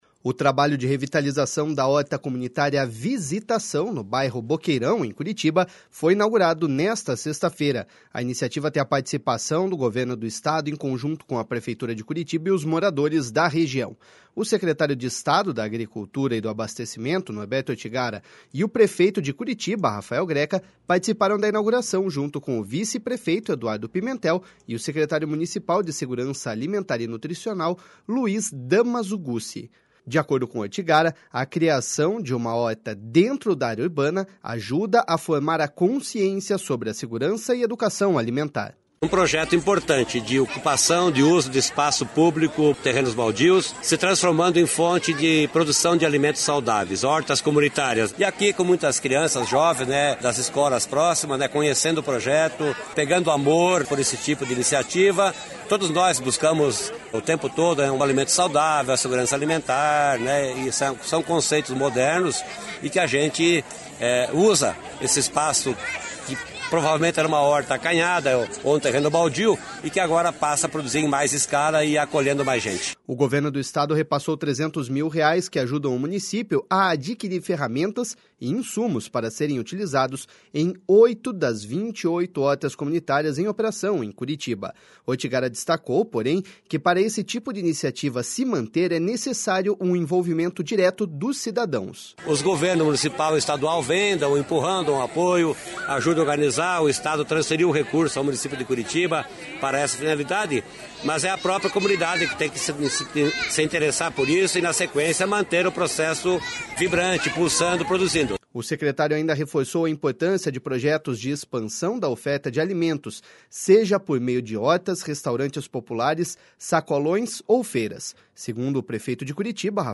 De acordo com Ortigara, a criação de uma horta dentro da área urbana ajuda a formar a consciência sobre a segurança e educação alimentar.// SONORA NORBERTO ORTIGARA.//
Segundo o prefeito de Curitiba, Rafal Greca, os espaços podem ser importantes não apenas para a produção de alimentos, mas também de plantas medicinais.// SONORA RAFAEL GRECA.//